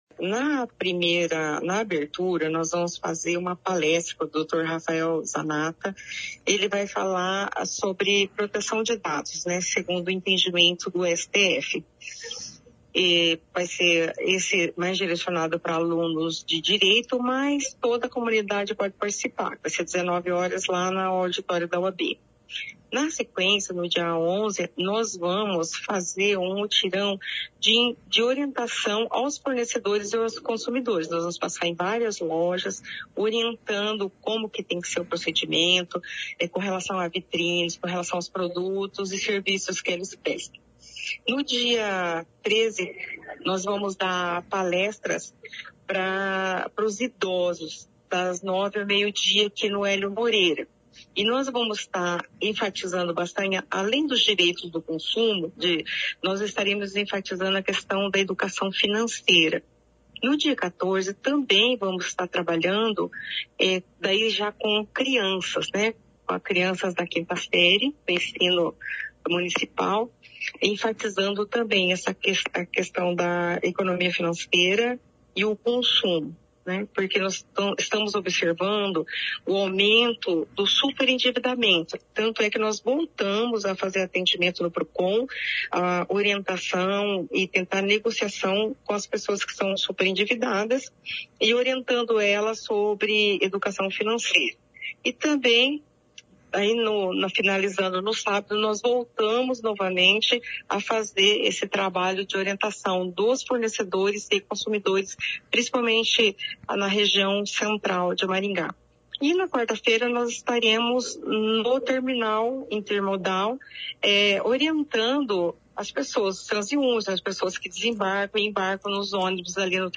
A coordenadora do Procon, Coronel Audilene Rocha, diz que o objetivo é levar informação e prevenir problemas que levam consumidores ao endividamento.